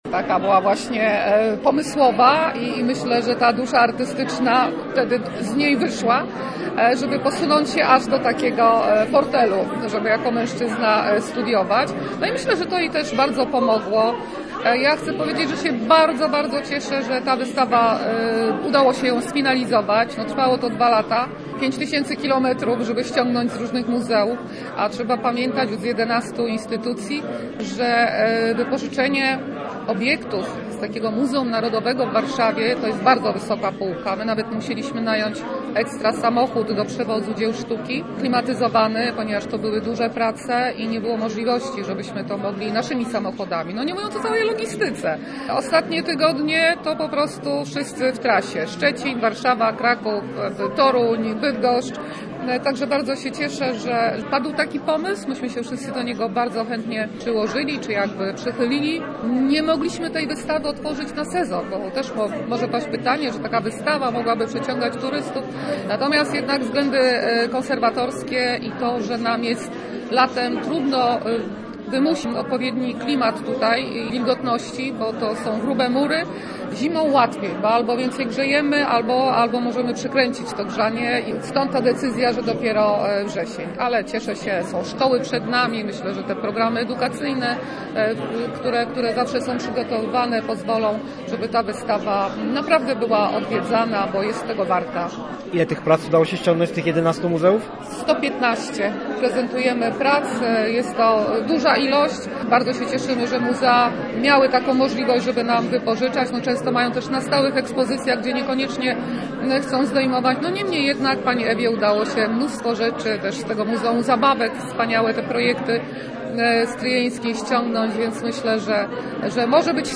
Posłuchaj relacji z wernisażu: https